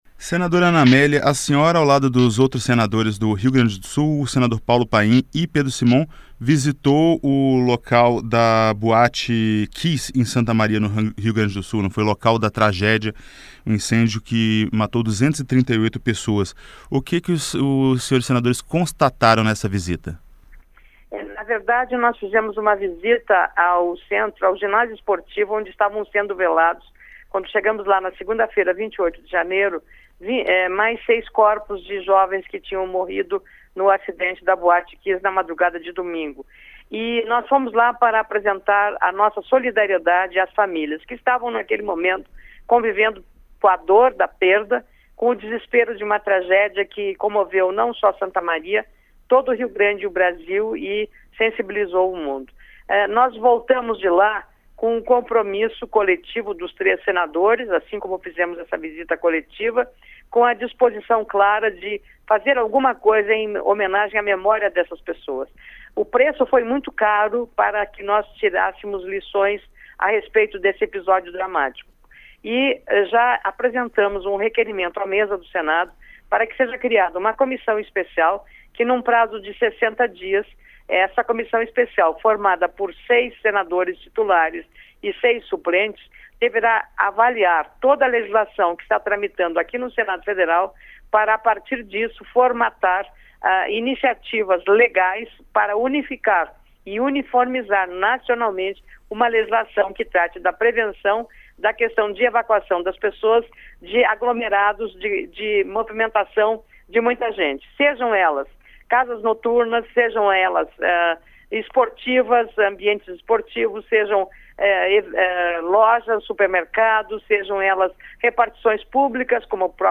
Comissão Especial quer regulamentar segurança em locais de eventos Entrevista com a senadora Ana Amélia (PP-RS).